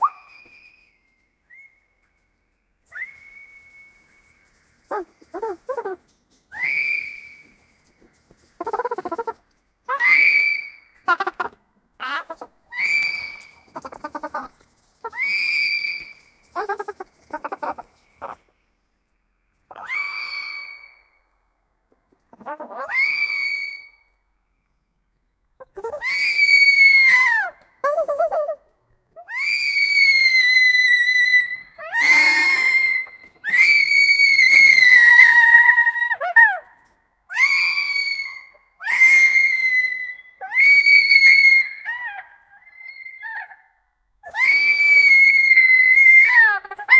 "female scream echo", "frogs croaking rapidly", "snakes hissing close-up"
female-scream-echo---pks3oaxk.wav